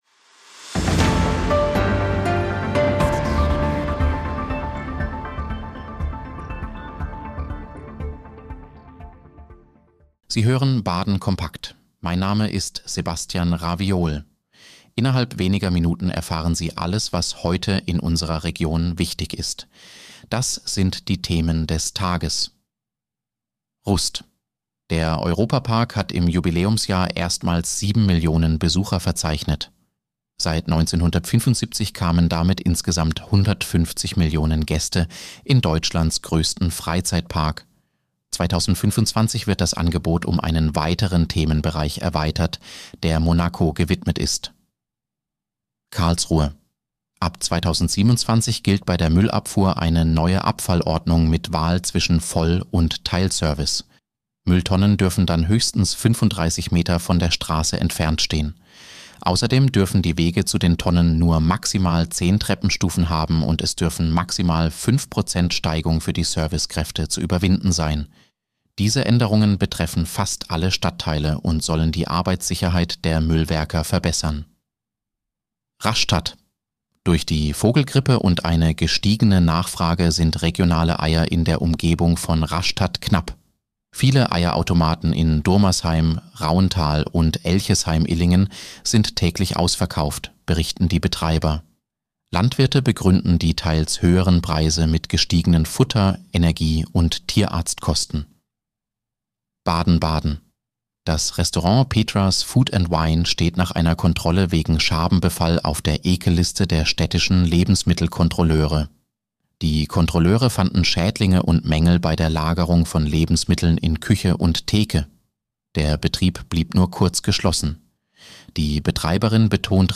Nachrichtenüberblick Dienstag, 13. Januar 2026